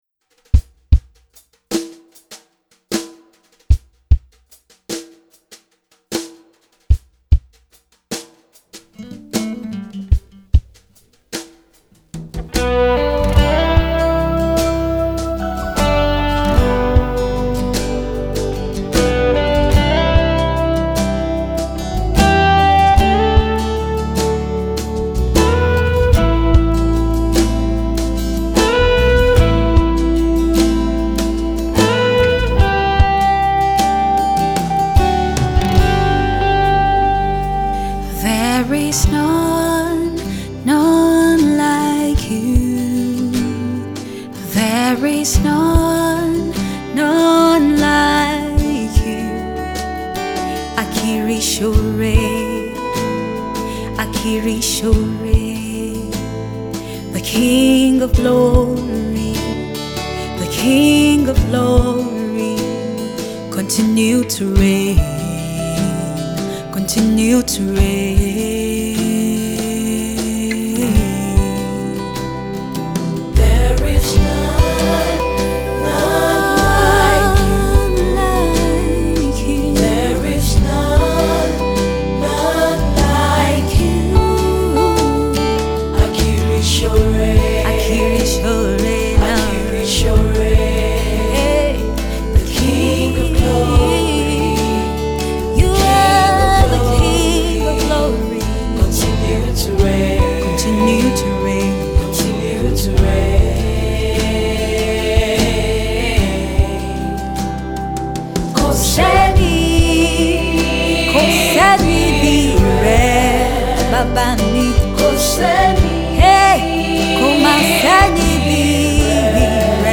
a song of worship to the One who goes about doing good